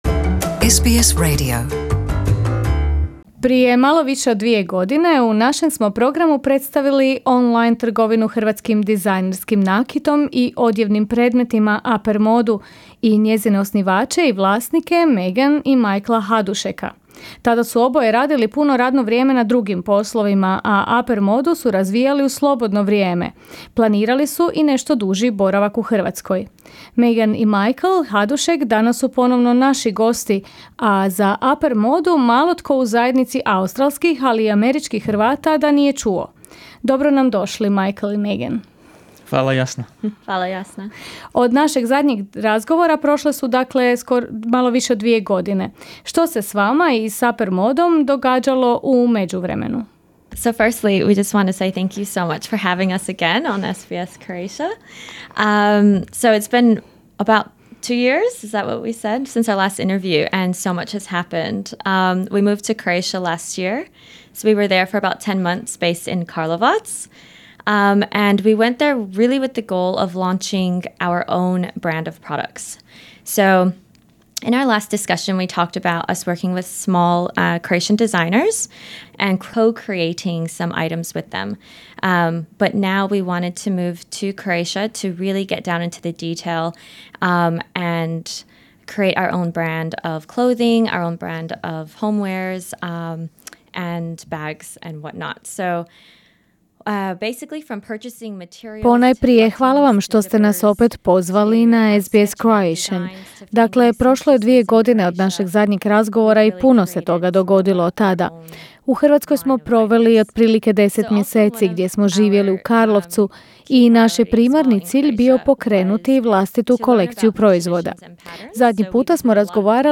Sada su ponovo u Australiji, Uppermoda je sve prisutnija u zajednici australskih, ali i američkih i kanadskih Hrvata, pa smo s njima razgovarali o tome što se promijenilo u zadnje dvije godine.